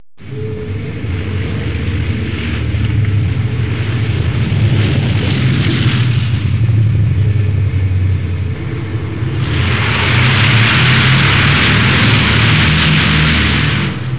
دانلود آهنگ طیاره 2 از افکت صوتی حمل و نقل
دانلود صدای طیاره 2 از ساعد نیوز با لینک مستقیم و کیفیت بالا
جلوه های صوتی